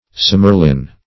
smerlin - definition of smerlin - synonyms, pronunciation, spelling from Free Dictionary Search Result for " smerlin" : The Collaborative International Dictionary of English v.0.48: Smerlin \Smer"lin\, n. (Zool.)